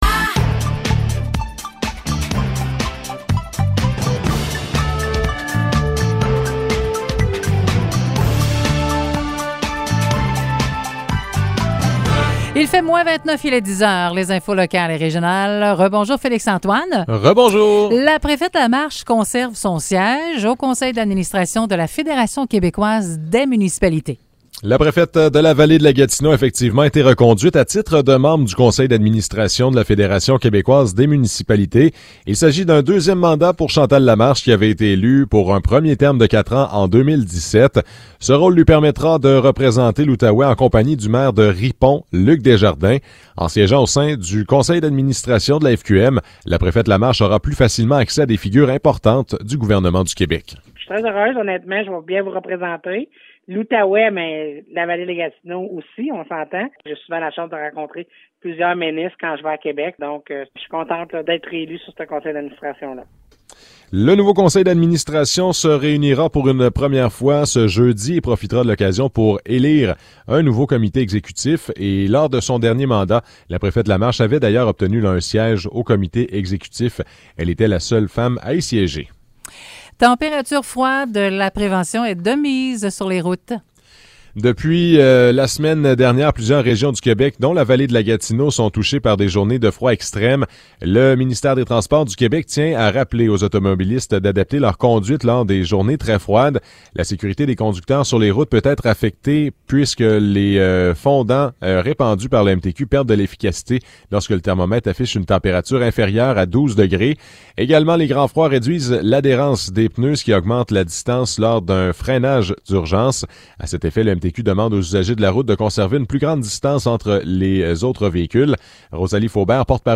Nouvelles locales - 26 janvier 2022 - 10 h